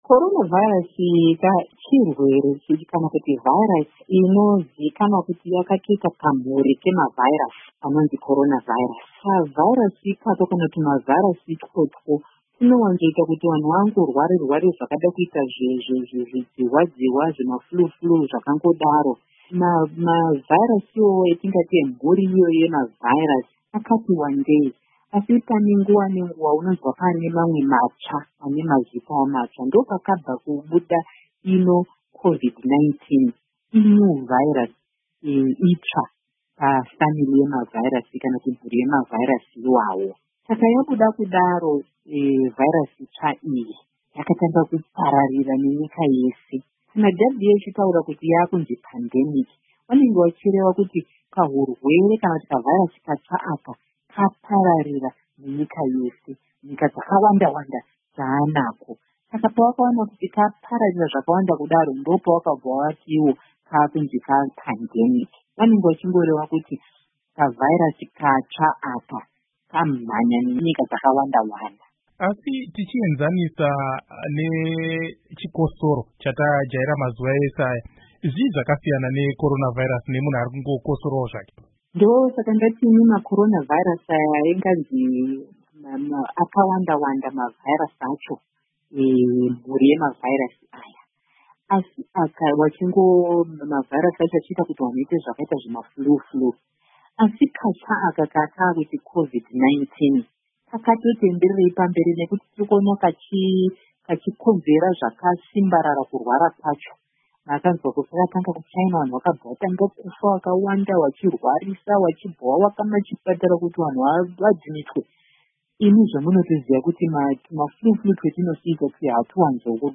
Hurukuro naDr Agnes Mahomva